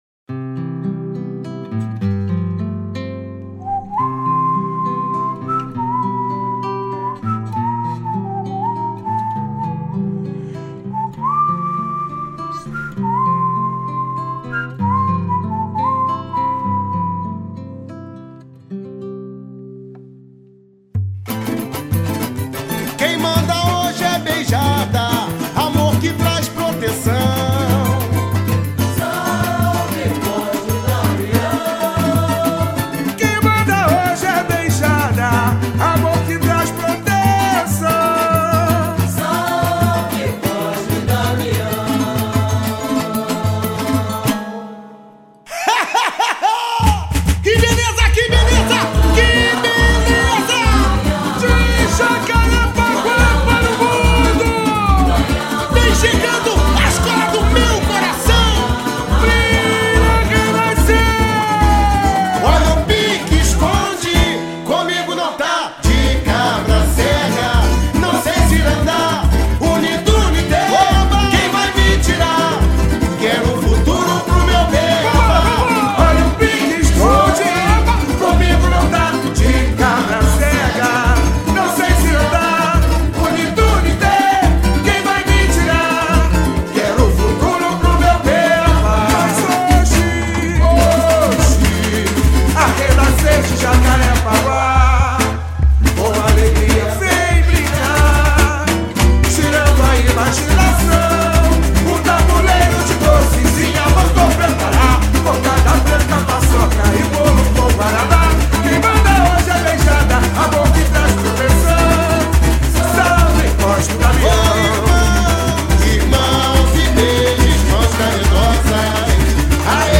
sambão